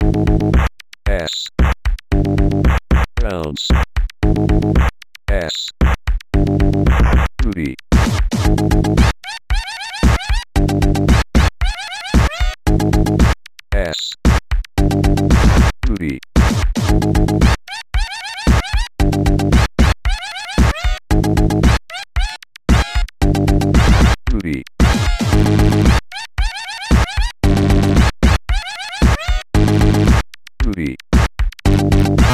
chech ça c'est un mini morceau ghette tech